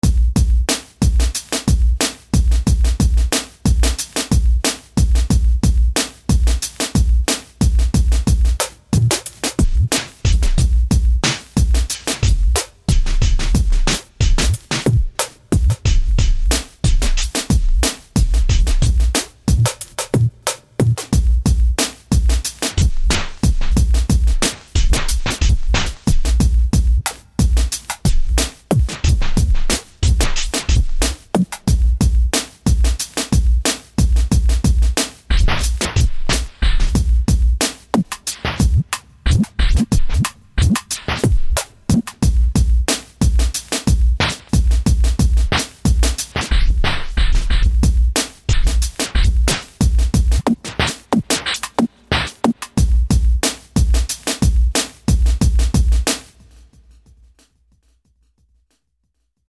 It’s a perfectly timed effect that NEVER goes out of Sync / time because of the way I have pulled this effect off.
This is giving you guys back some of that raw sound of the decks without the timing issues.
hip-hop-bends.mp3